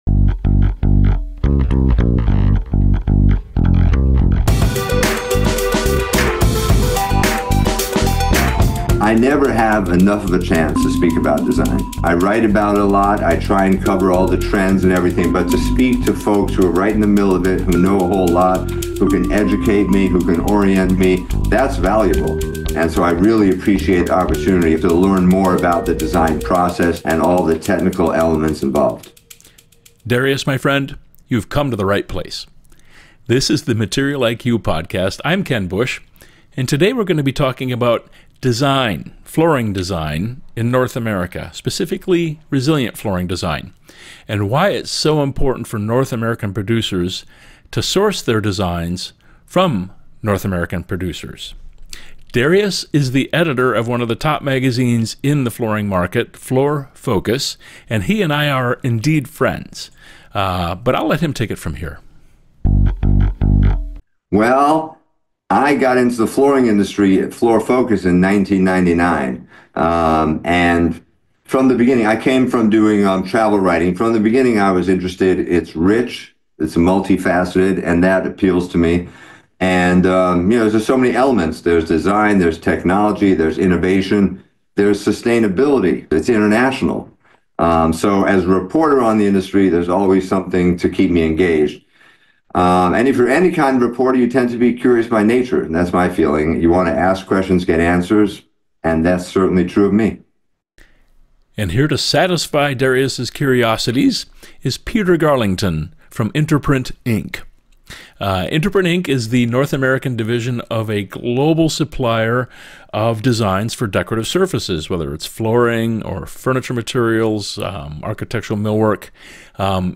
A deep-dive discussion about resilient flooring designs that sell in North America